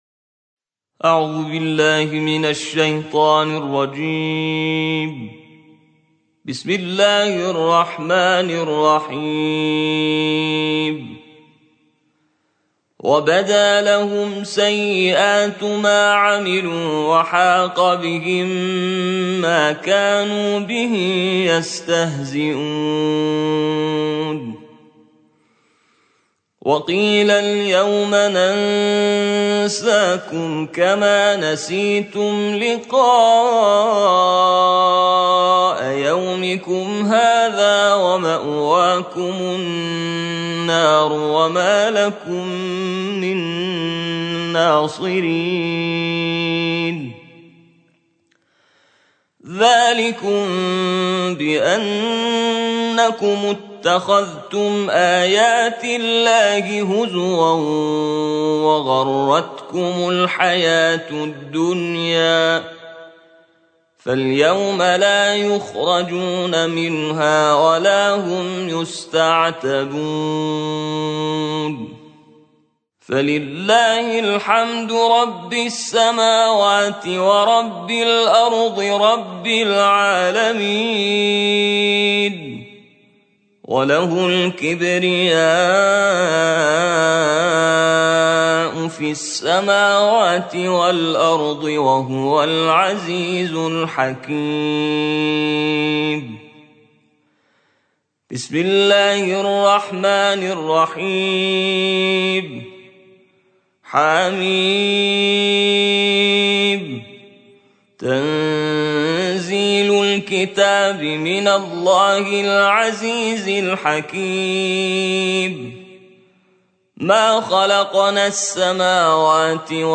ترتیل جزء 26 قرآن